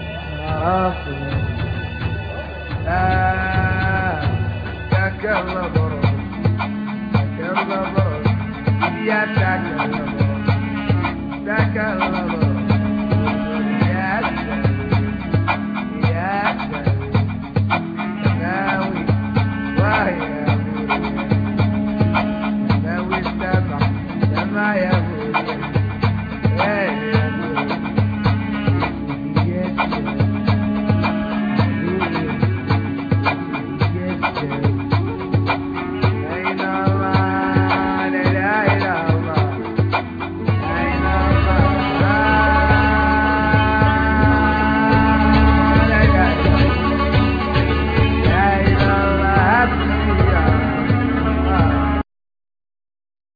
Voice,Poetry,Samples
Violin
Singing